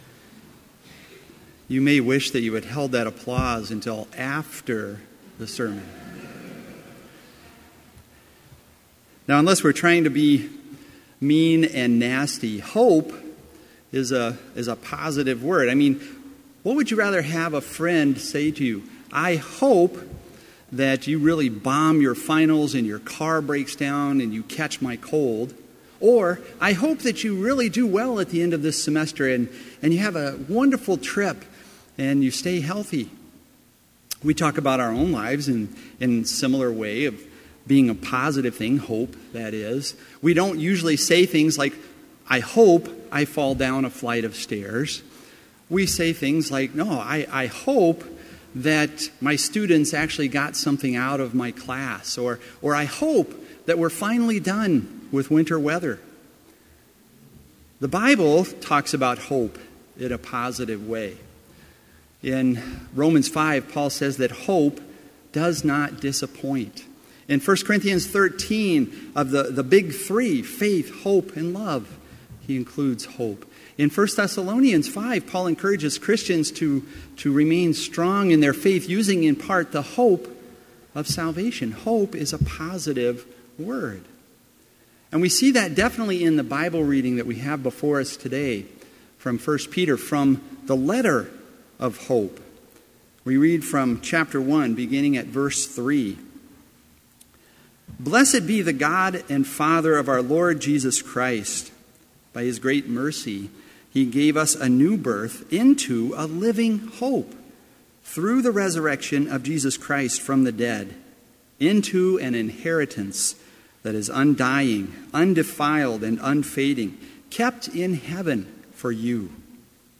Complete Service
• Devotion
This Chapel Service was held in Trinity Chapel at Bethany Lutheran College on Wednesday, April 25, 2018, at 10 a.m. Page and hymn numbers are from the Evangelical Lutheran Hymnary.